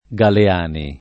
Galeani [ g ale # ni ]